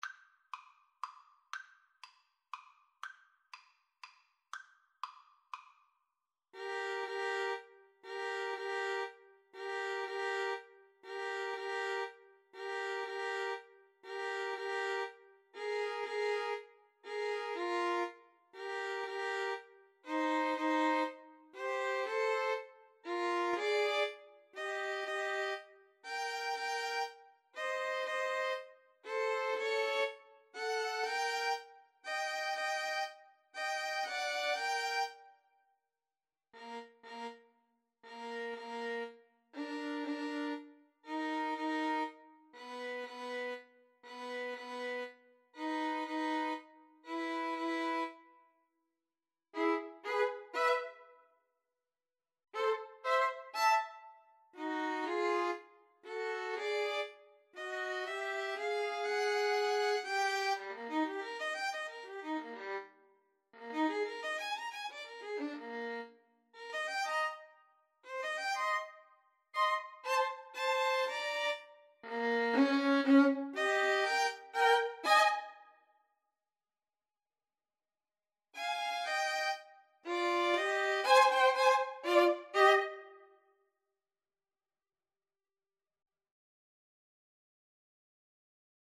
Eb major (Sounding Pitch) (View more Eb major Music for 2-Violins-Cello )
Allegretto pomposo = c.120
2-Violins-Cello  (View more Intermediate 2-Violins-Cello Music)